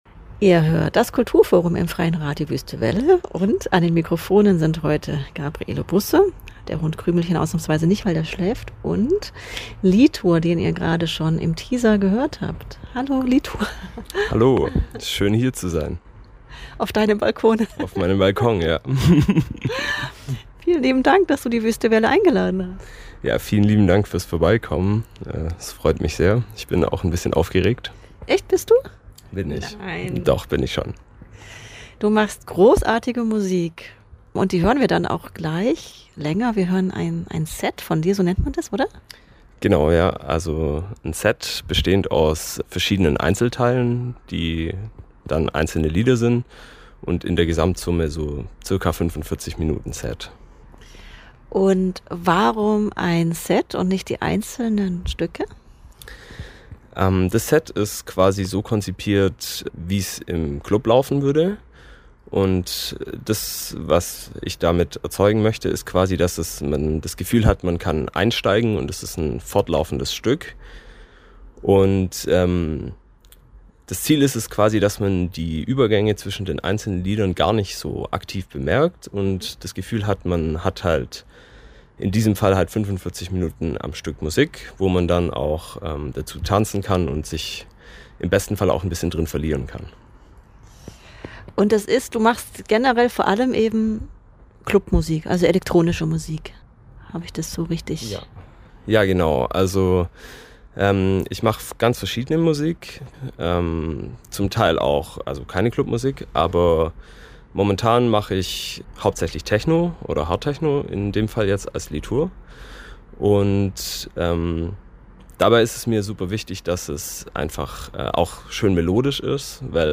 Sein Techno ist anders als das, was er bisher kannte: Zu den treibenden Beats gesellen sich eingängige melodiöse Anteile.